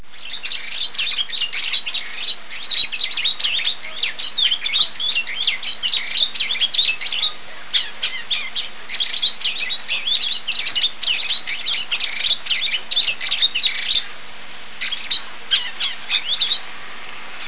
Early in the morning, the birds ~ doves, mockingbirds, sparrows, cardinals, woodpeckers, and bluejays ~ chirp out the prettiest symphony
(listen to my birds) , and late in the evening one may hear the faint sound of a whipoorwhill off in the distance (hear the whipporwhill) , or maybe a mockingbird holding out for a late night beneath the light of a streetlamp.
Chirp1.wav